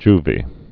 (jvē)